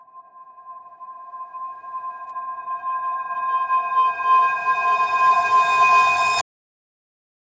AV_Suspense_Riser
AV_Suspense_Riser.wav